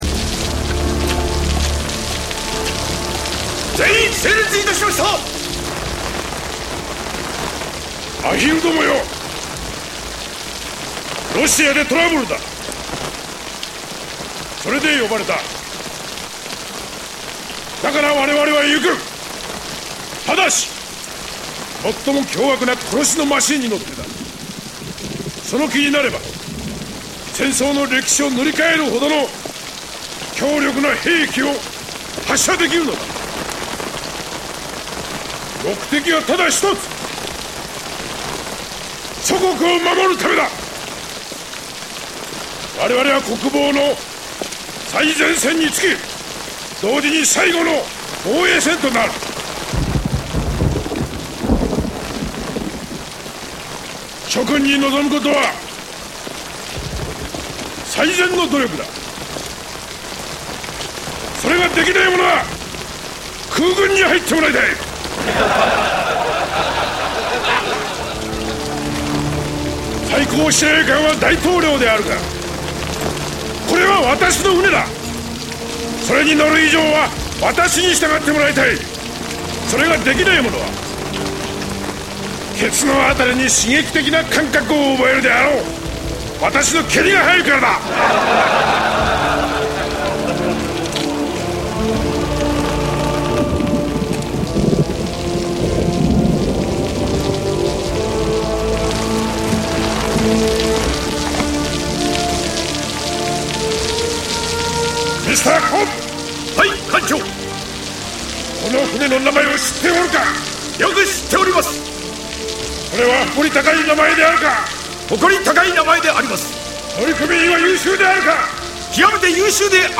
Japanese audio for the little speech.
crimsontidespeech.mp3